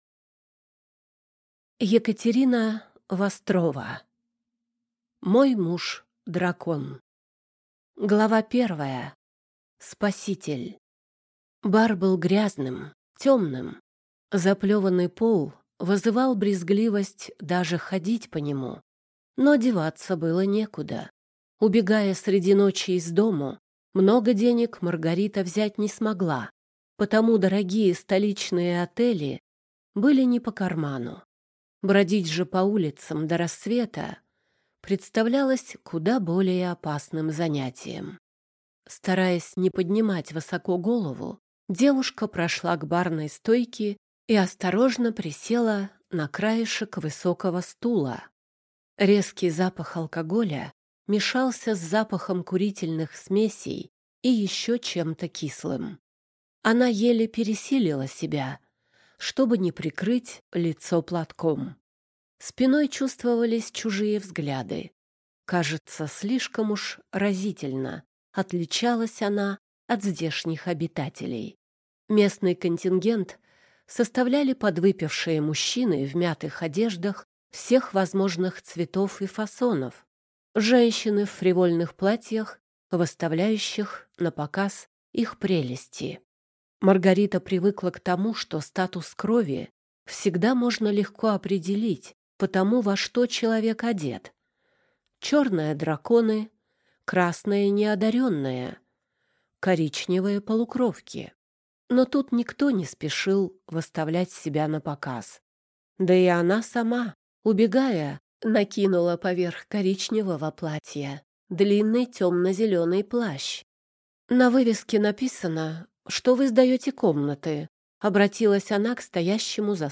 Аудиокнига Мой муж – дракон | Библиотека аудиокниг